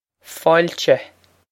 foyle-cha
This is an approximate phonetic pronunciation of the phrase.